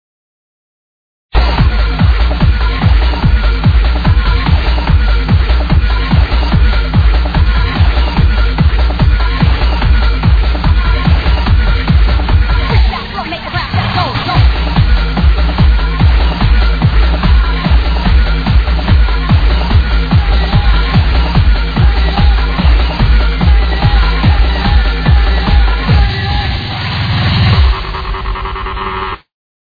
tranceaddict
indeed an id is in order. quite a thumper we have hear
Wow this sounds like an nice hard tune